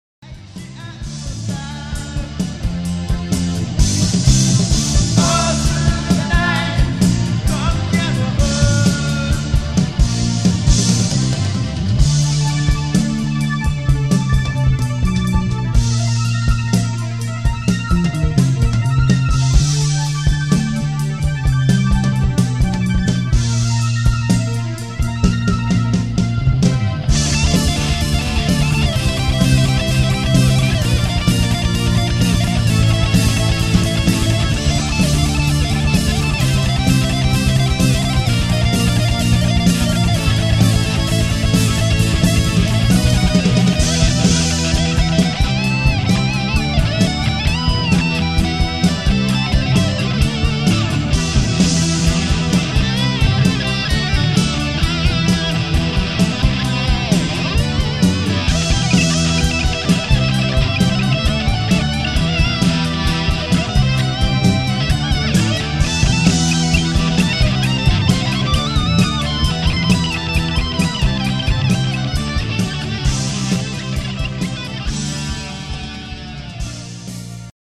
中間部のギターソロの前のプログレシブなインストゥルメンタル・パートは印象的だ。